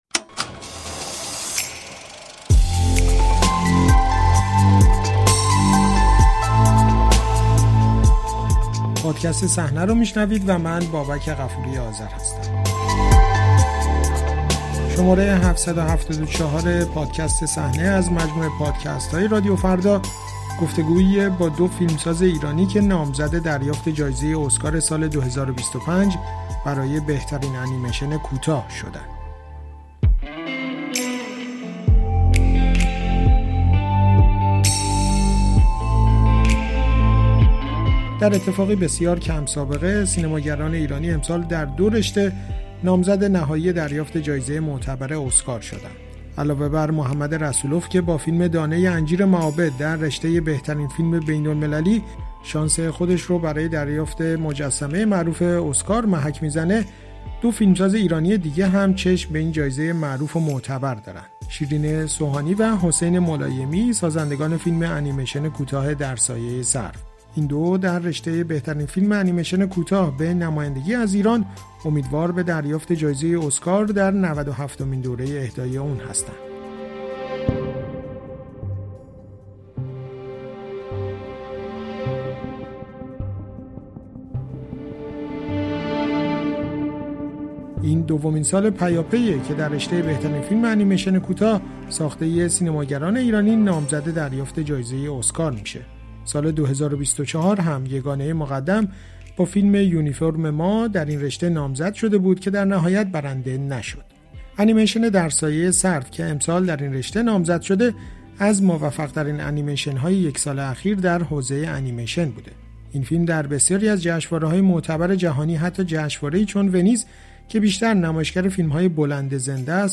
گفت‌وگو با دو نامزد ایرانی اسکار ۲۰۲۵ برای بهترین انیمیشن کوتاه